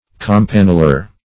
campanular \cam*pan"u*lar\ (k[a^]m*p[a^]n"[-u]*l[~e]r), adj.
campanular.mp3